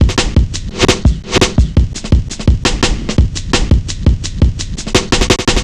Index of /90_sSampleCDs/Zero-G - Total Drum Bass/Drumloops - 3/track 58 (170bpm)